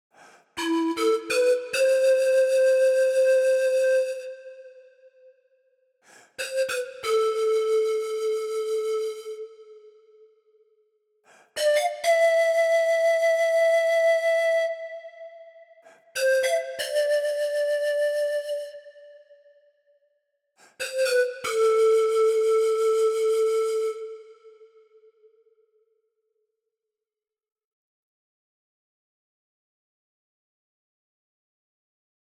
Chromatic Pan Flute features a large Peruvian pan flute, ready to play right away.
The hard dynamic layer is activated by powerful playing with high velocity on your keyboard, producing samples with an intense vibrato.